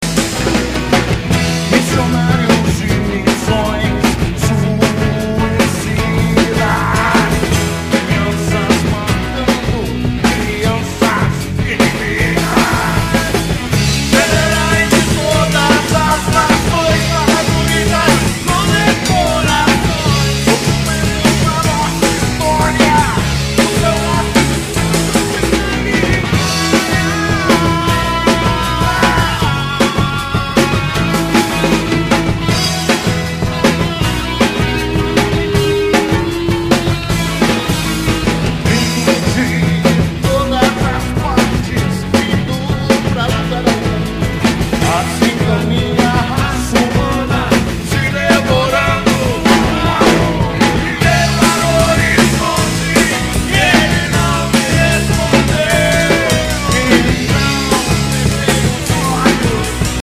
No último domingo, duas gerações do rock jacutinguense estiveram reunidas no Jacka Studio.
fizeram uma jam session.
rock ‘n’ roll